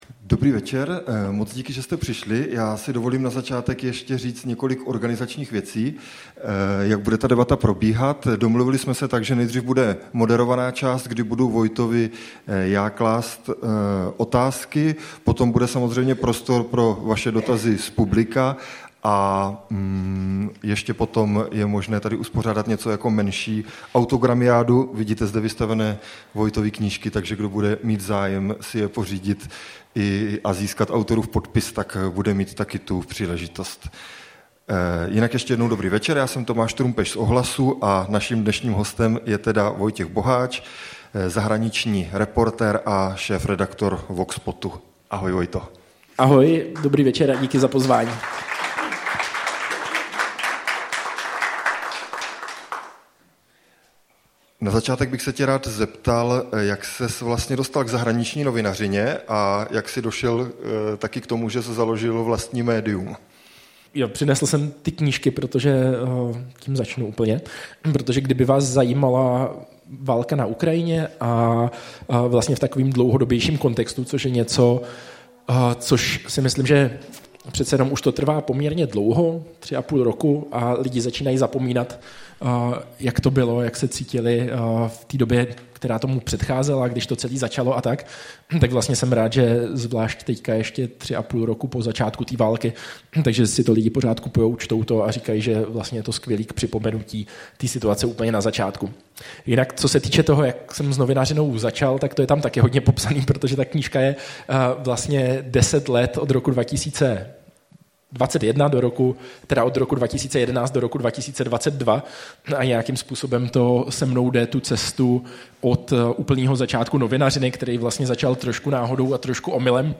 Rozhovor
proběhl v Letovicích v rámci oslav 36. výročí Sametové revoluce v předvečer státního svátku.